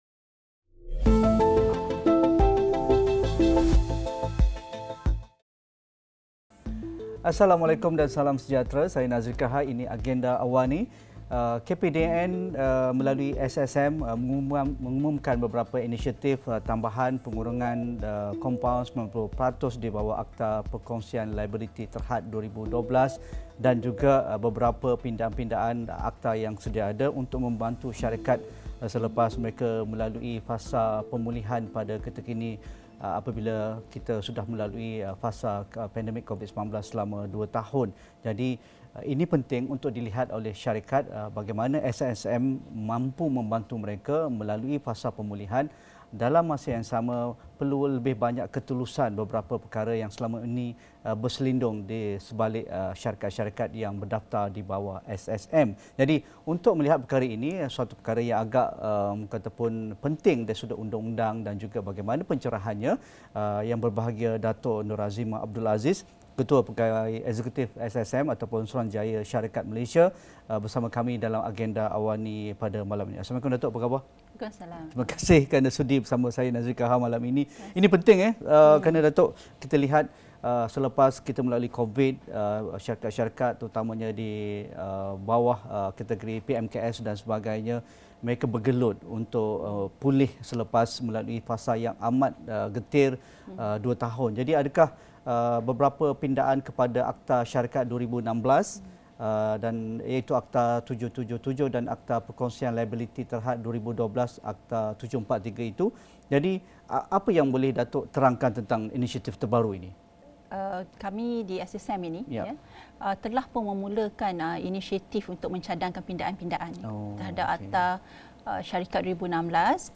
Apakah inisiatif-inisiatif yang diperkenalkan oleh Suruhanjaya Syarikat Malaysia (SSM) dalam pindaan Akta Syarikat 2016 (Akta 777) dan Akta Perkongsian Liabiliti Terhad 2012 (Akta 743)? Diskusi 9 malam